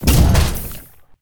CosmicRageSounds / ogg / general / combat / battlesuit / move2.ogg